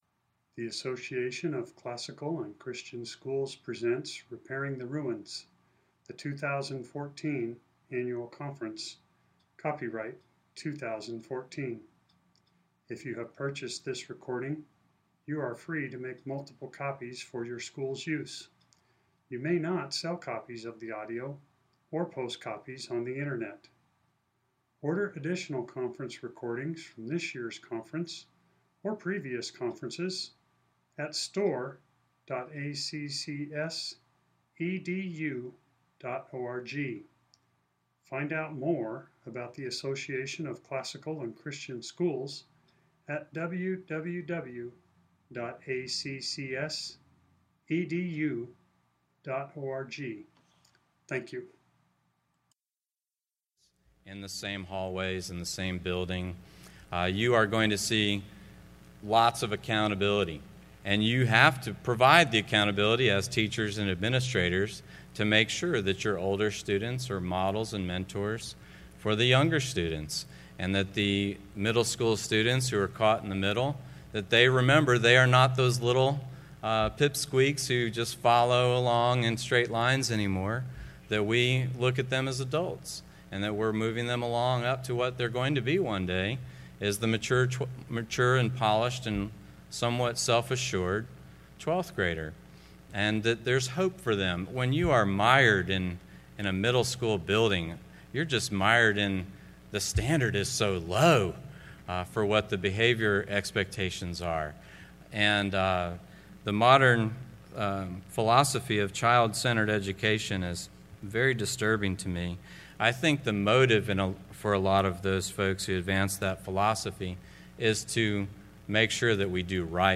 2014 Workshop Talk | 0:58:42 | All Grade Levels
The Association of Classical & Christian Schools presents Repairing the Ruins, the ACCS annual conference, copyright ACCS.